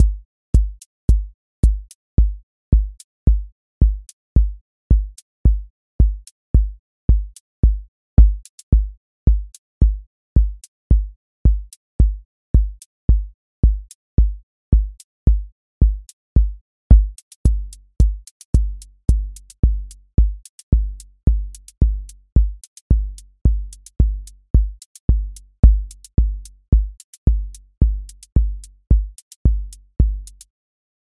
QA Listening Test house Template: four_on_floor
steady house groove with lift return
• macro_house_four_on_floor
• voice_kick_808
• voice_hat_rimshot
• voice_sub_pulse